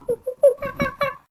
sounds_monkey_03.ogg